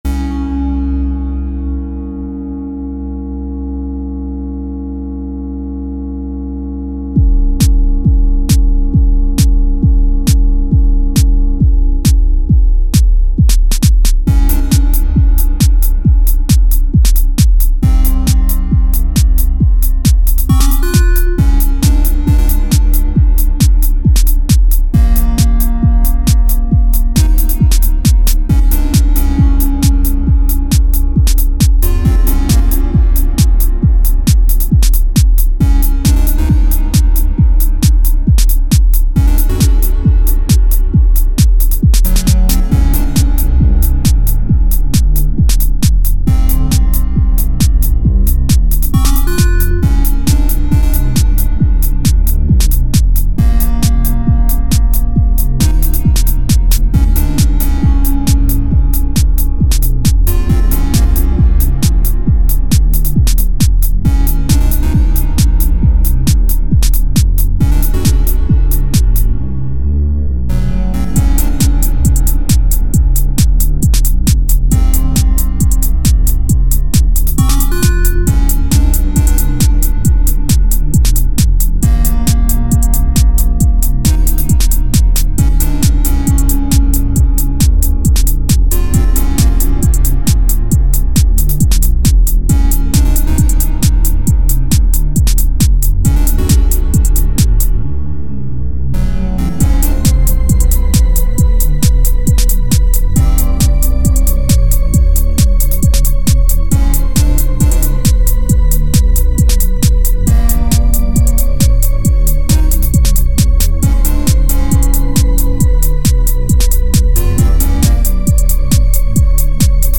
so kühl, minimalistisch lässig und sexy steril
Minimaler Plastikfunk aus dem Kühlhaus.